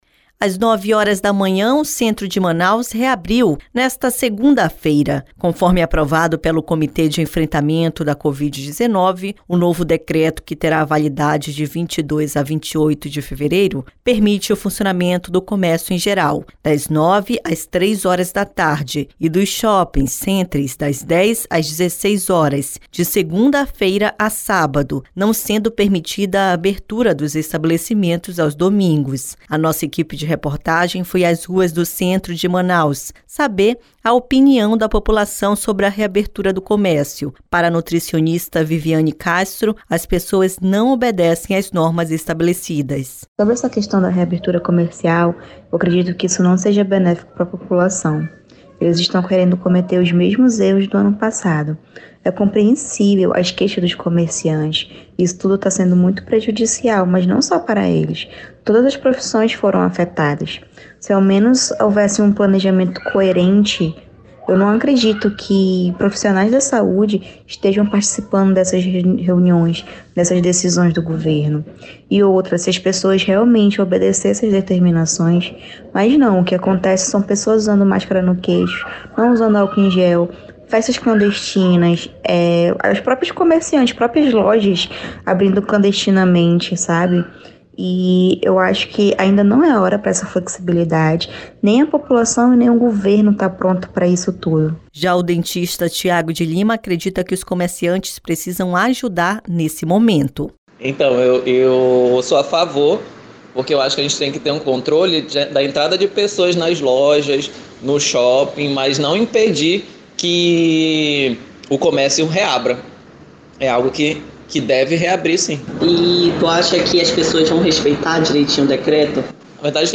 A nossa equipe de reportagem foi as ruas do centro de Manaus saber o a opinião da população sobre a reabertura do comércio.